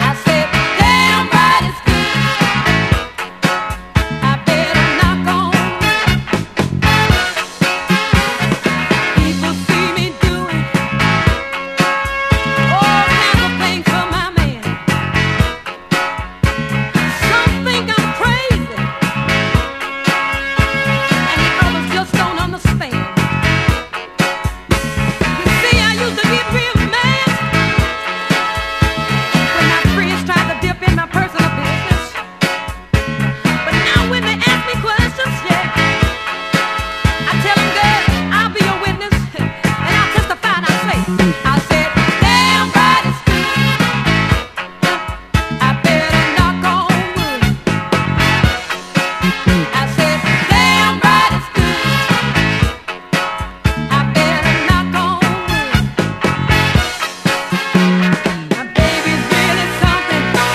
SOUL / SOUL / 70'S～ / DISCO